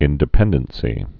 (ĭndĭ-pĕndən-sē)